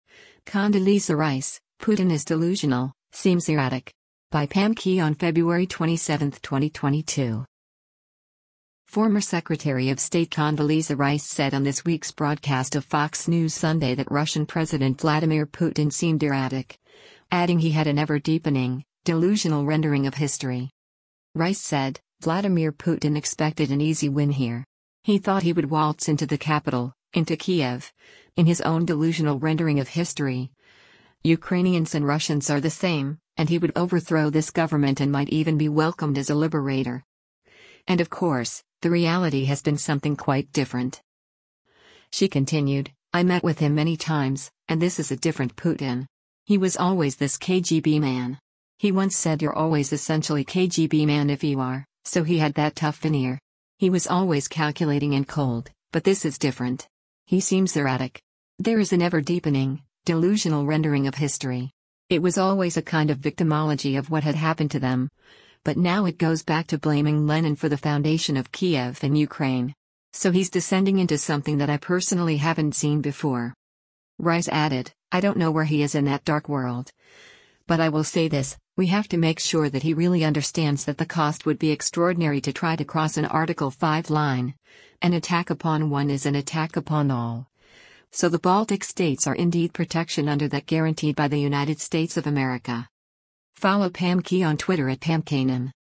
Former Secretary of State Condoleezza Rice said on this week’s broadcast of “Fox News Sunday” that Russian President Vladimir Putin seemed “erratic,” adding he had an “ever-deepening, delusional rendering of history.”